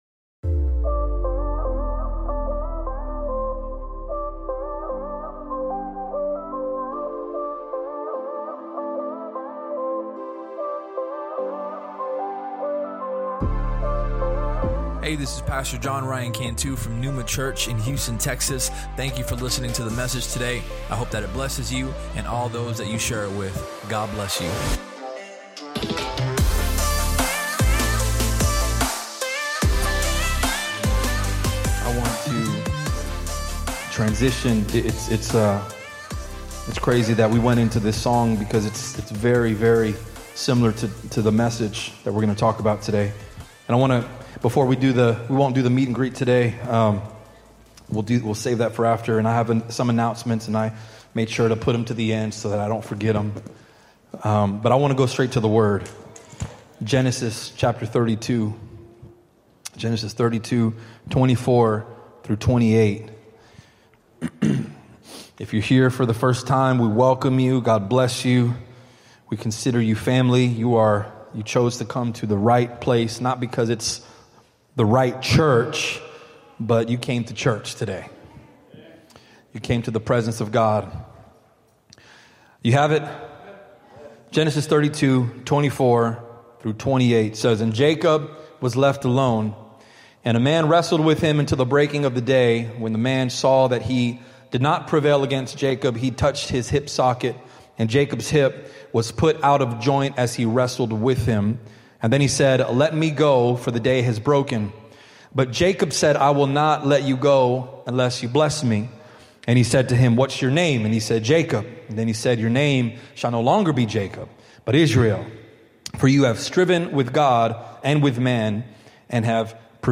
Related Sermon: The Struggle Sermon Topics: Identity, Struggle If you enjoyed the podcast, please subscribe and share it with your friends on social media.